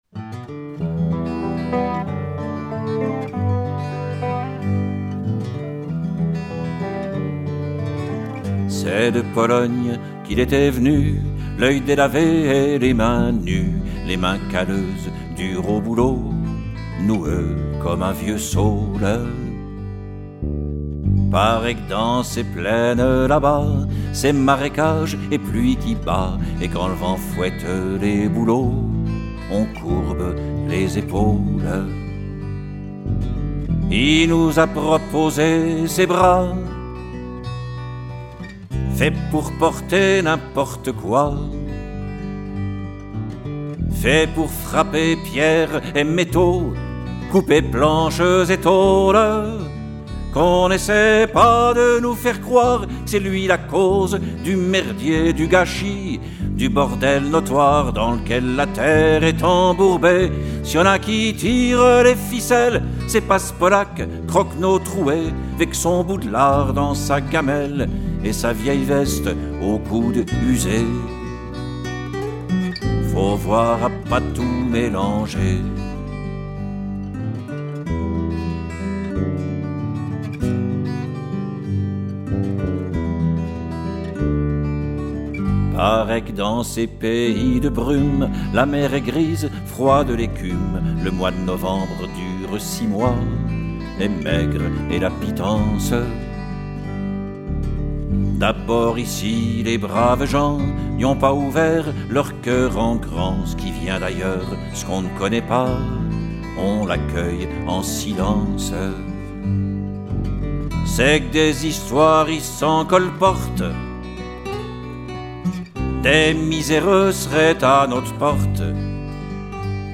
Voix, guitares, instrument midi